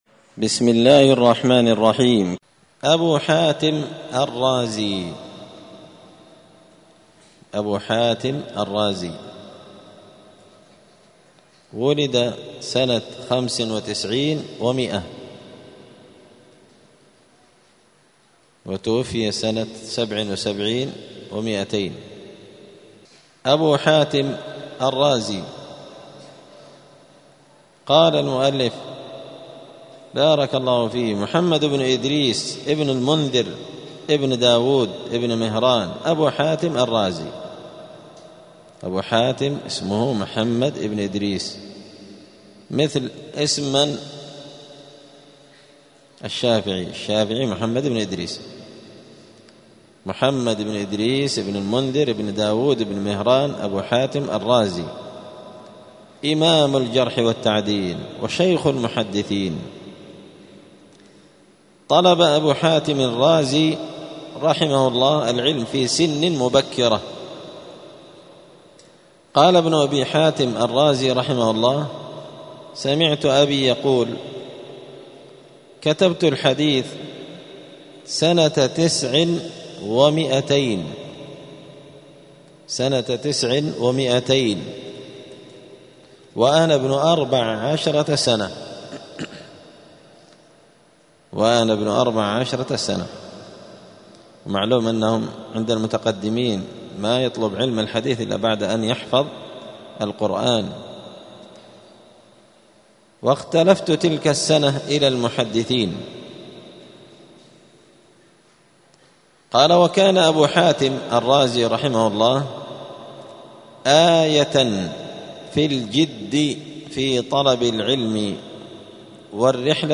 *الدرس الثامن والثمانون (88) باب التعريف با لنقاد أبو حاتم الرازي*